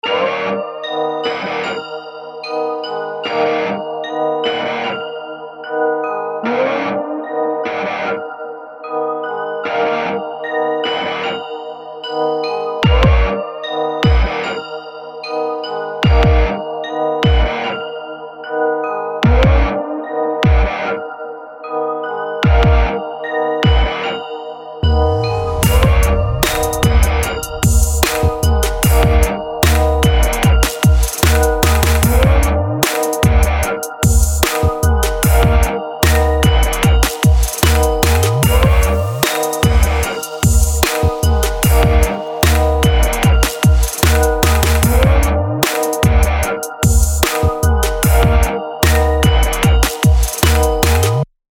.MK 2 קטע ראפ.mp3